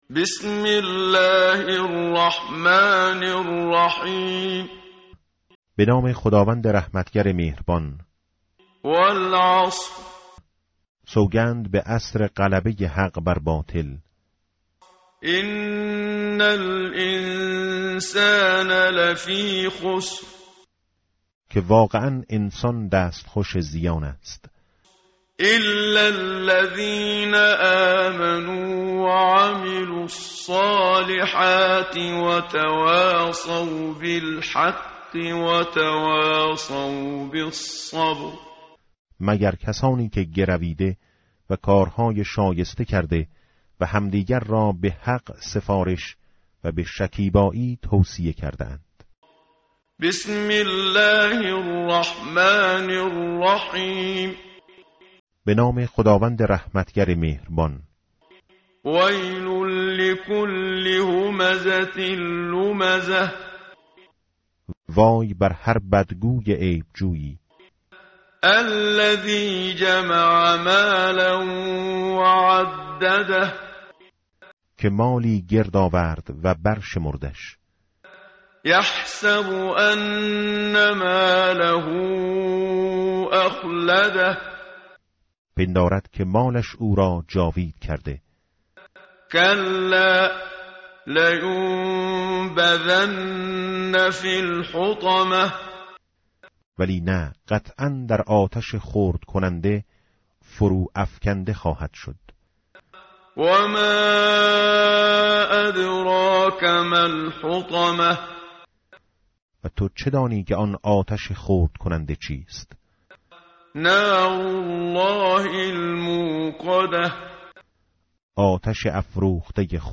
متن قرآن همراه باتلاوت قرآن و ترجمه
tartil_menshavi va tarjome_Page_601.mp3